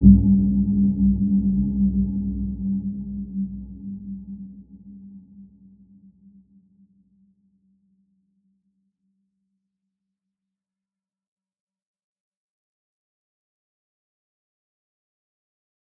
Dark-Soft-Impact-G3-mf.wav